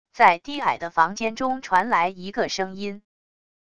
在低矮的房间中传来一个声音wav音频